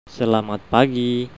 speaker.gif (931 bytes) Click on the word to hear it pronounced.